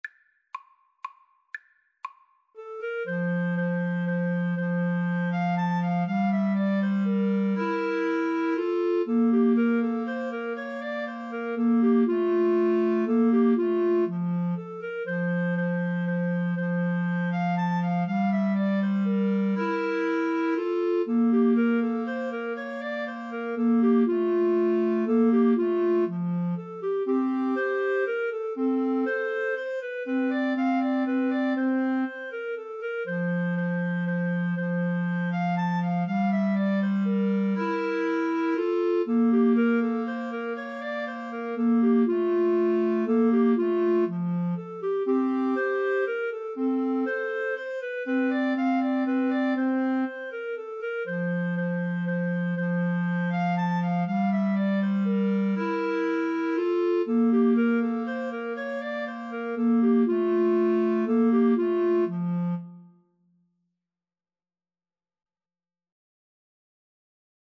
3/4 (View more 3/4 Music)
Clarinet Trio  (View more Easy Clarinet Trio Music)
Classical (View more Classical Clarinet Trio Music)